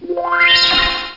Cosmic Opening Fast Sound Effect
Download a high-quality cosmic opening fast sound effect.
cosmic-opening-fast.mp3